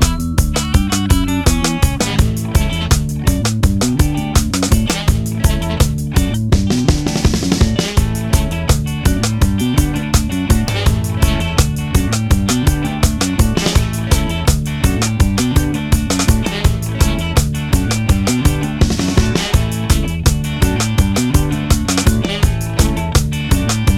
no Backing Vocals Ska 2:42 Buy £1.50